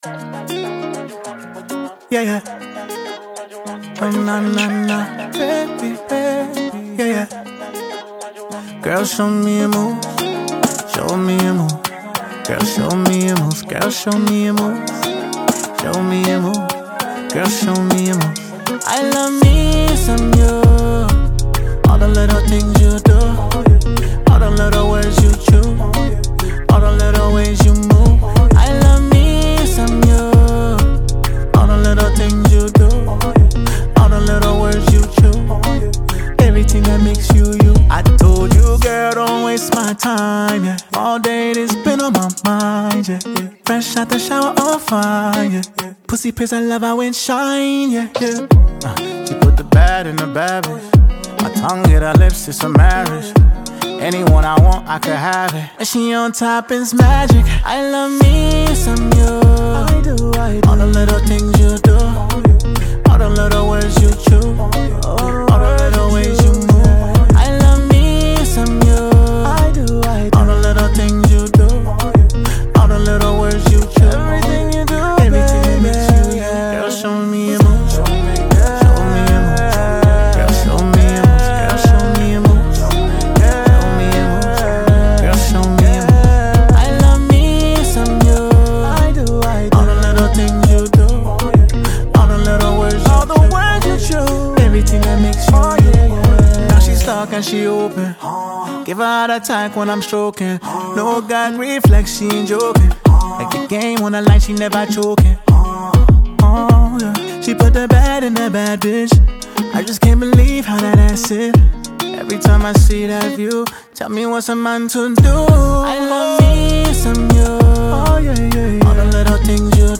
” blends Afrobeat with contemporary sounds
With its upbeat tempo and catchy sounds